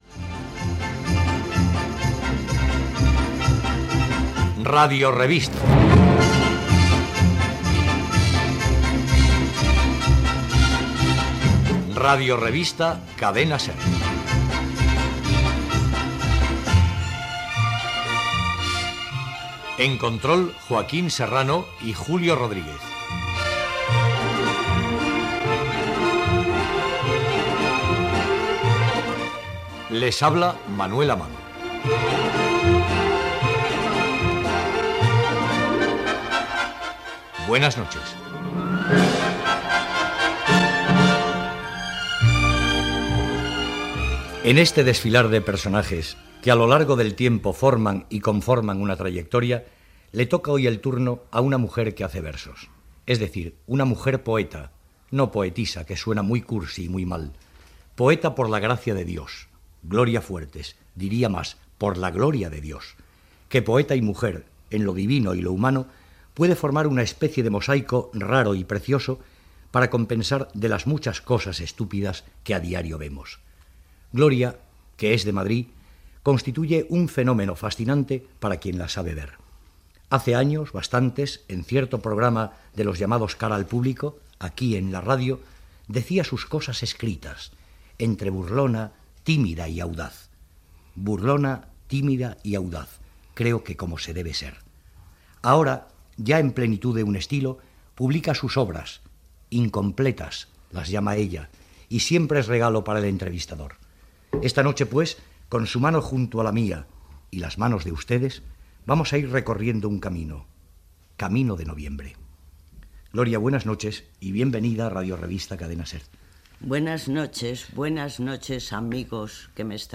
Careta del programa, equip, presentació i entrevista a la poeta Gloria Fuertes